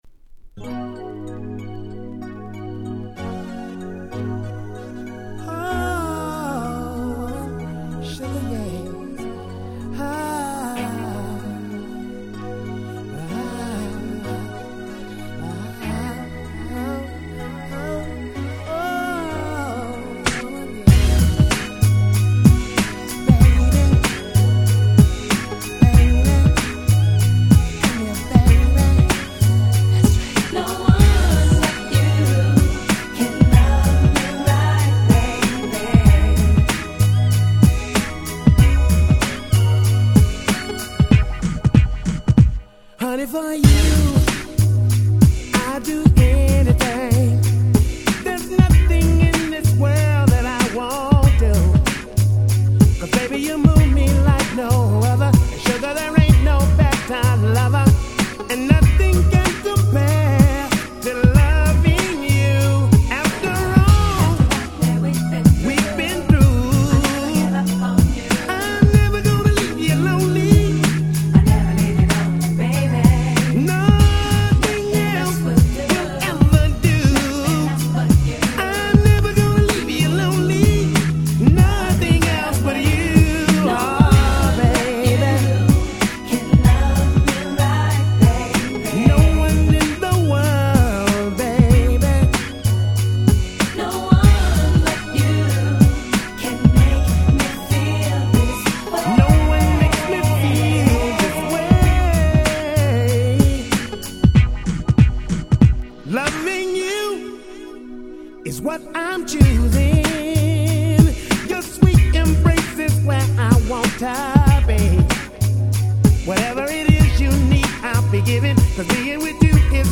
97' Very Nice R&B !!